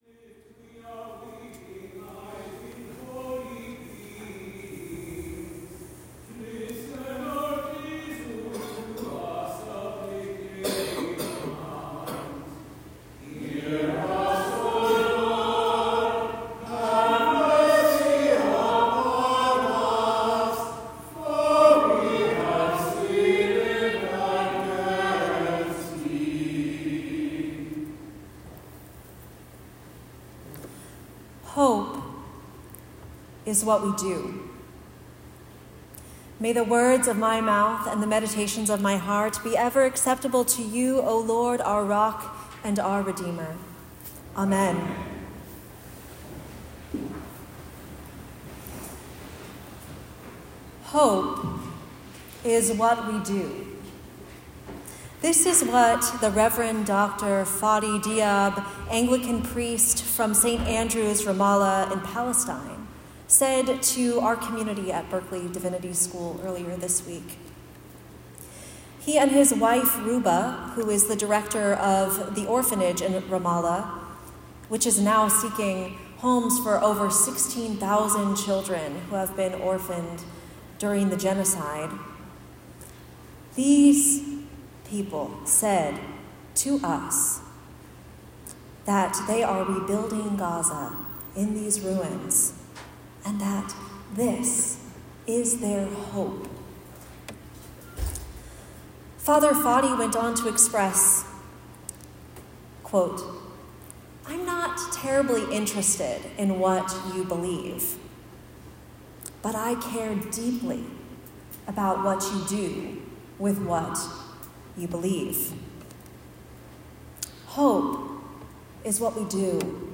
Sermon-3-9-St.-Martins-1.m4a